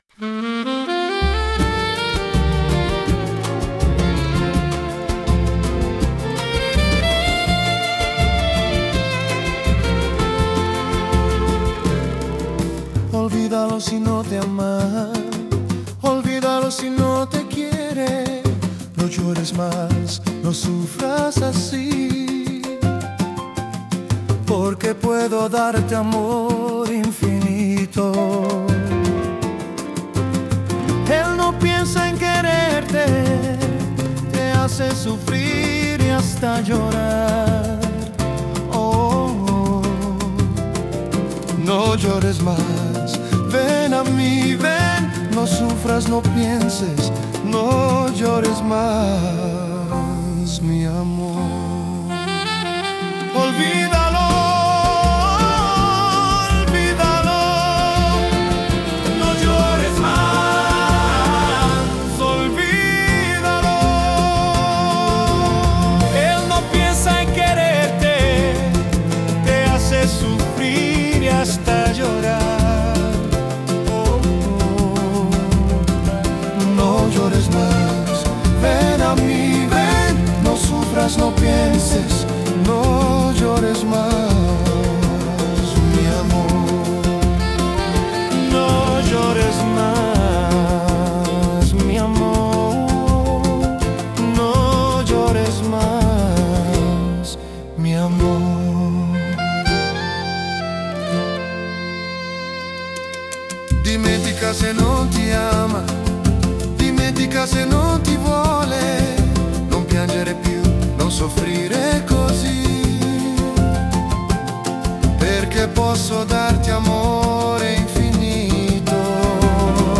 música, arranjo e voz: IA